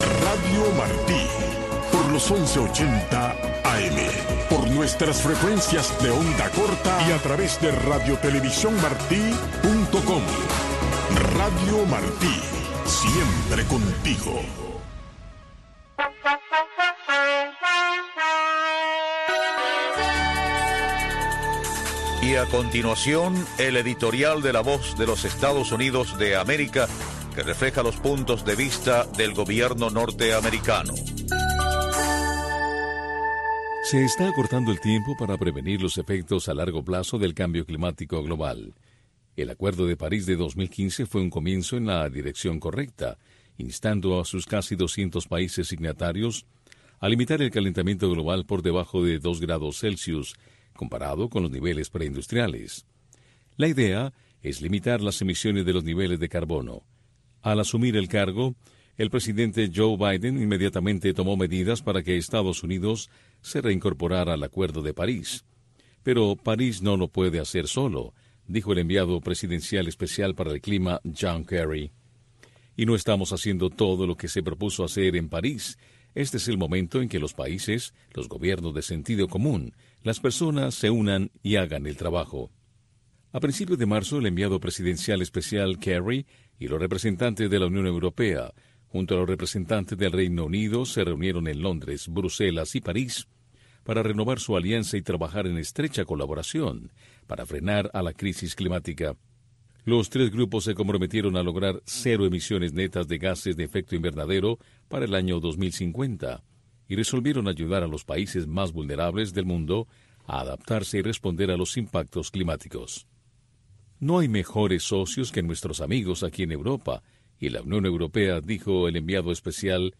La Santa Misa
PROGRAMACIÓN EN-VIVO DESDE LA ERMITA DE LA CARIDAD